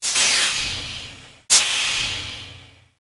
Famous Steam Burst Variations
SFX
yt_Dfpqa3MNw-A_famous_steam_burst_variations.mp3